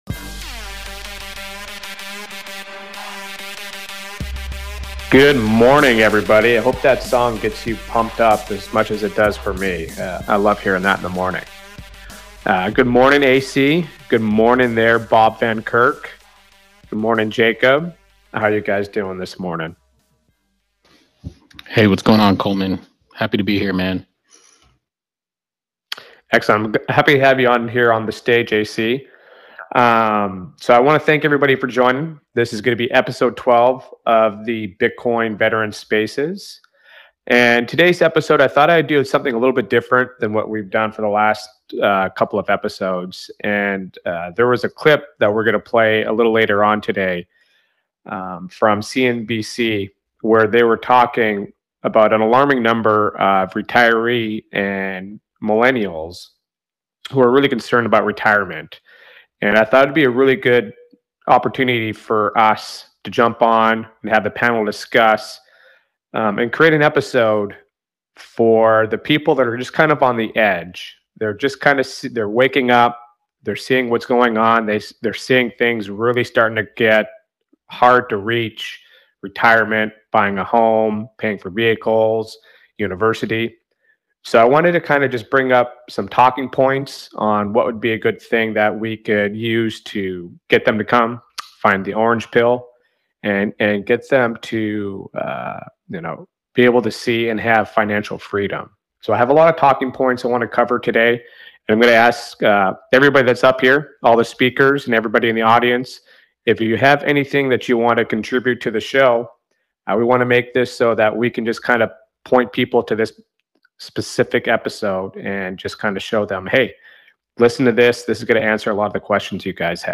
In this episode, we discussed the alarming concerns about retirement and home buying faced by many individuals, especially in light of rising costs and financial uncertainties. The panel delved into the impact of inflation on retirement savings, the increasing difficulty of affording homes, and the potential solutions offered by Bitcoin as a superior savings technology. The conversation highlighted the importance of educating individuals about the devaluation of fiat currency, the benefits of investing in Bitcoin, and the potential for financial freedom through strategic investment decisions.